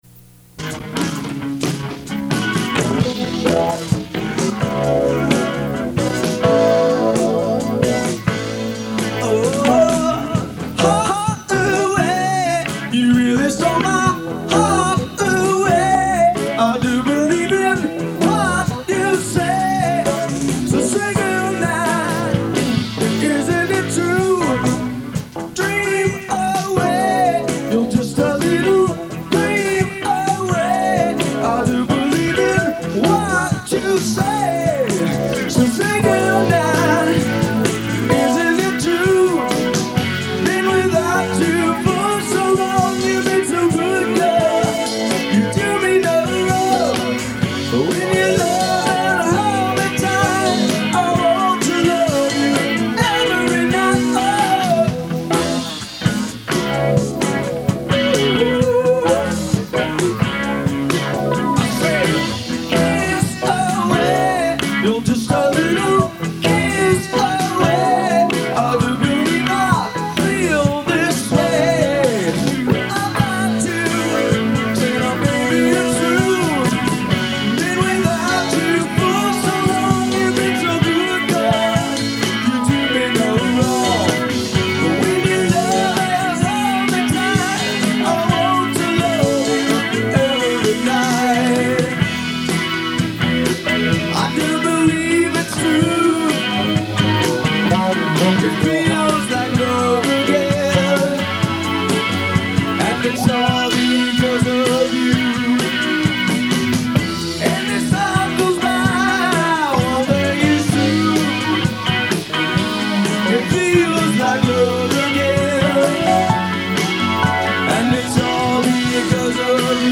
Laguna Canyon Studios, Laguna Beach, New Year's Eve, 1982
Circa 1981 - 82: I recorded this while engineering it with a live audience.